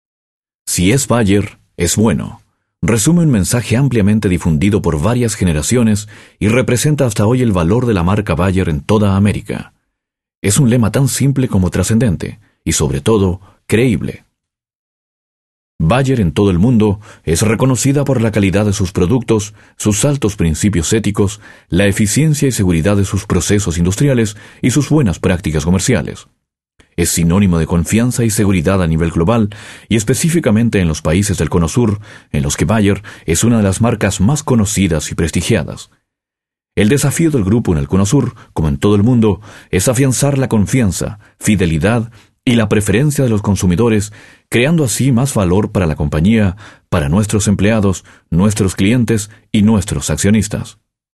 Sprecher, Spanisch südamerikanisch, chilenisch.
spanisch Südamerika
Sprechprobe: Werbung (Muttersprache):